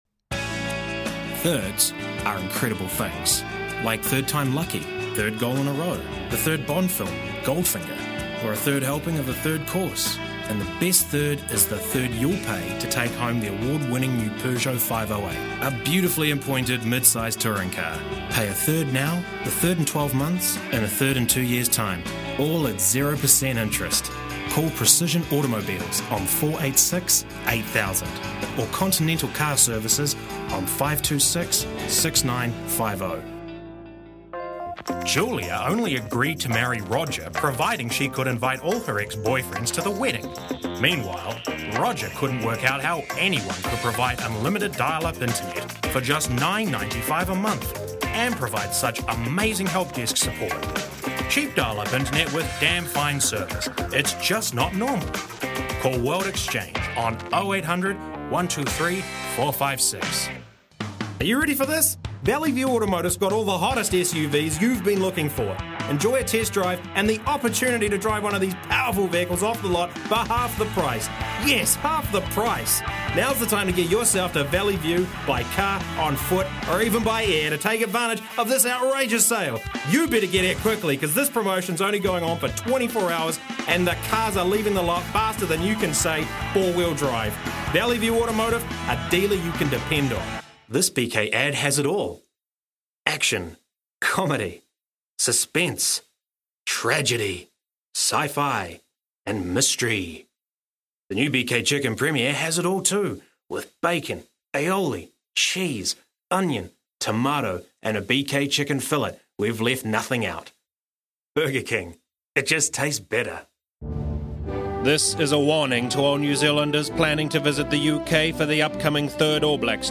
Male
Adult (30-50)
Very versatile, can do American, Australian, British, Russian, German accents. Capable of smooth sounding corporate narrative, enthusiastic commercial, and very capable of many character voices - comical or serious.
Various Accents/Characters
Radio Advert Reel